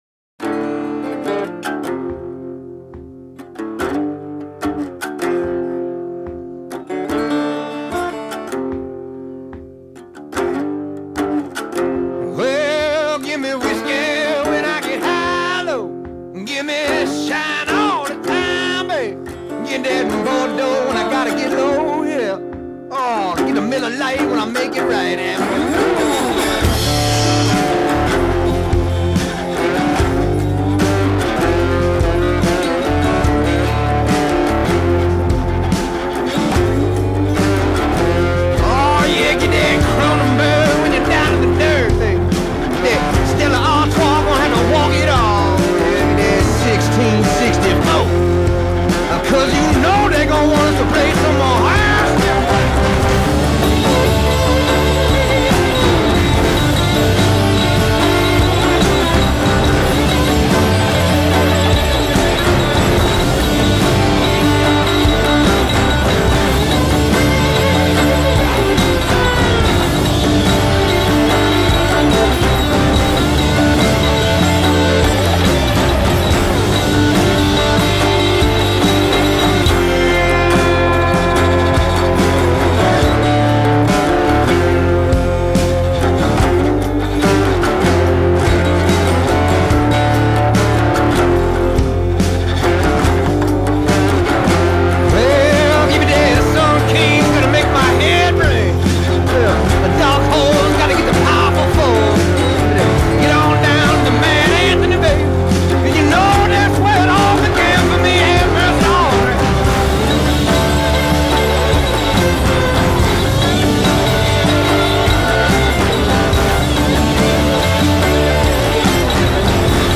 10 more tracks of some hard ass Blues.